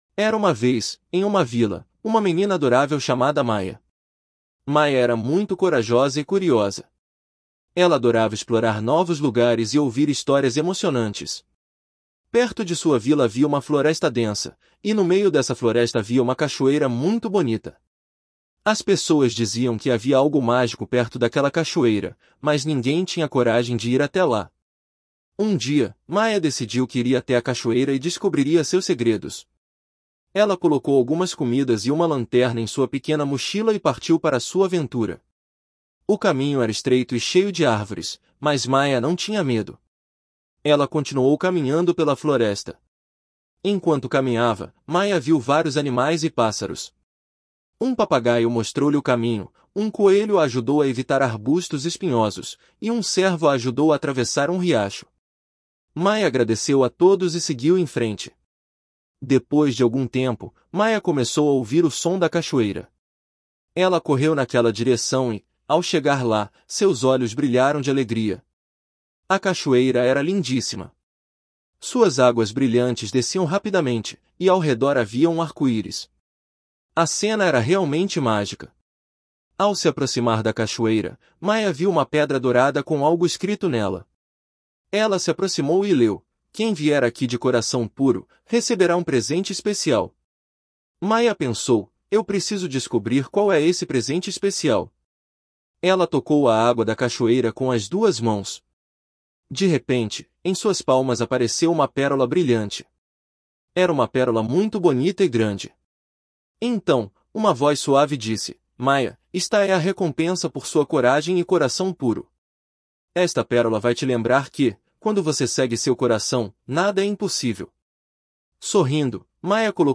A Jornada Mágica de Maya até a Cachoeira Conto infantil curto